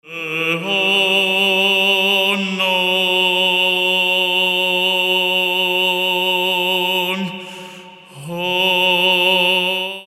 monodic